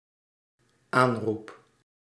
Ääntäminen
IPA : /ˈkɔː.lɪŋ/ IPA : /ˈkɔ.lɪŋ/